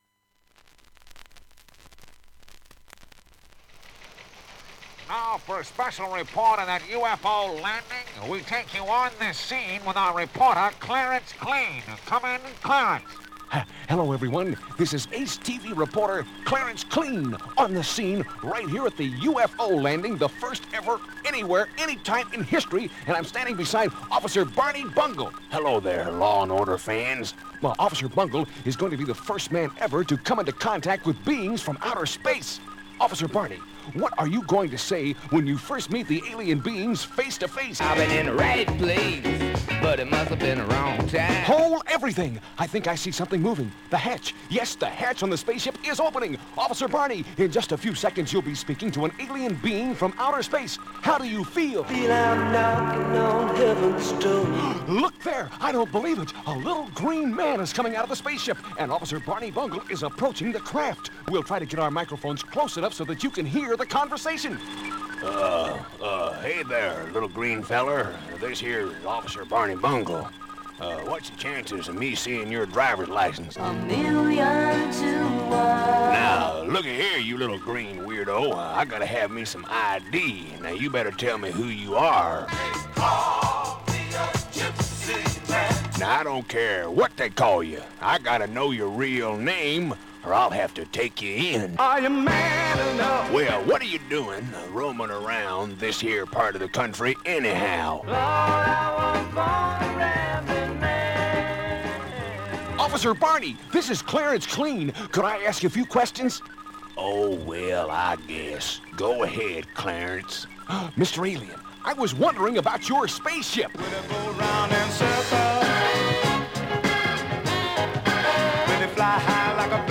しゃべり
ファンキー